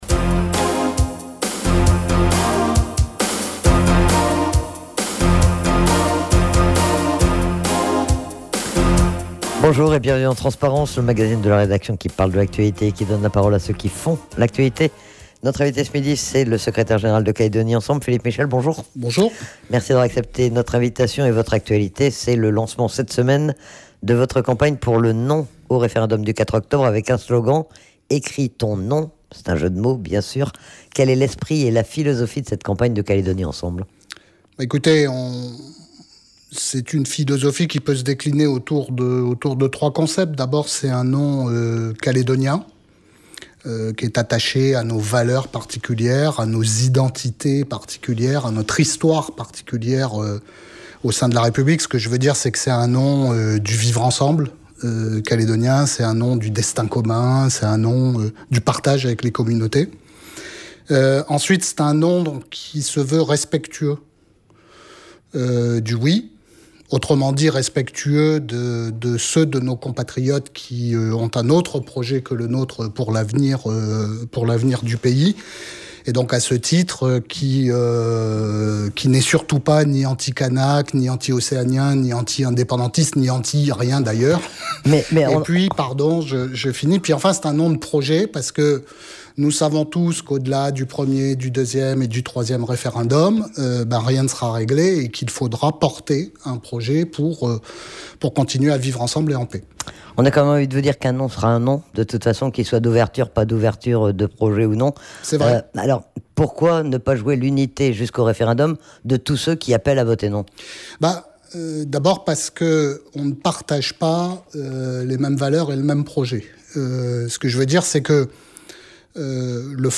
Il était aussi interrogé, plus globalement, sur l'actualité politique calédonienne.